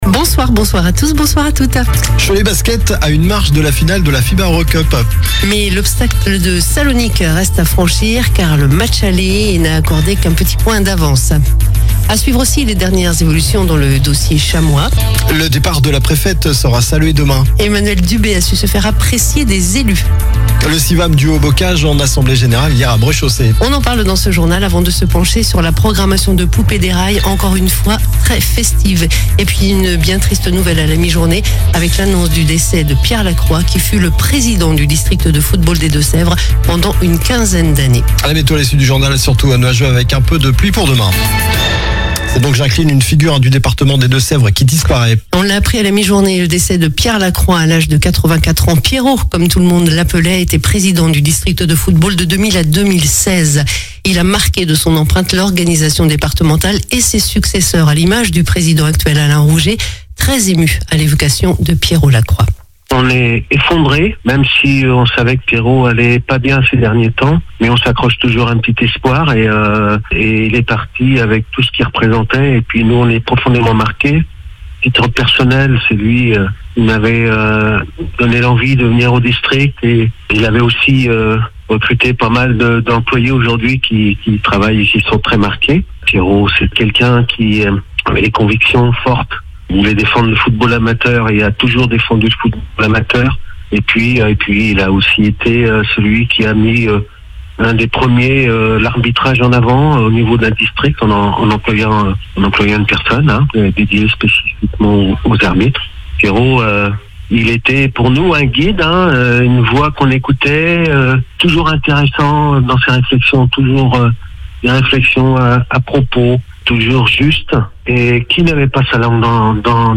Journal du mercredi 02 avril (soir)
infos locales